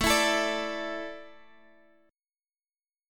Asus4 Chord (page 2)
Listen to Asus4 strummed